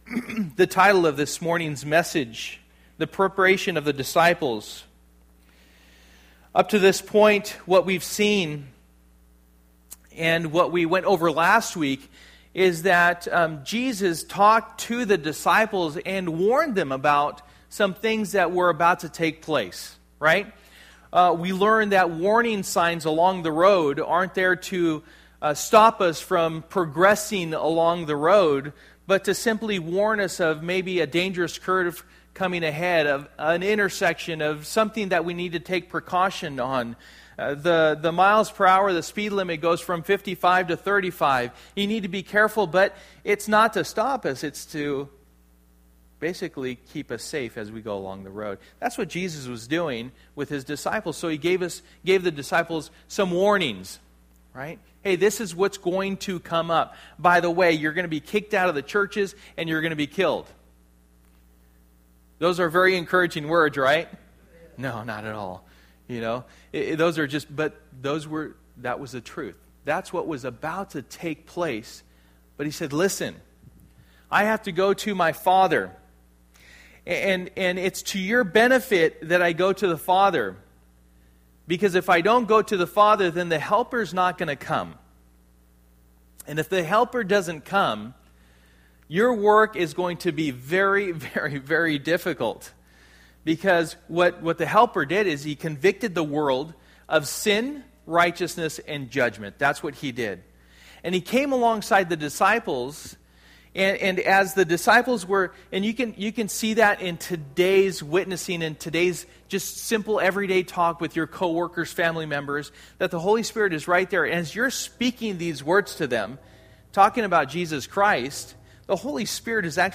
Passage: John 16:16-33 Service: Sunday Morning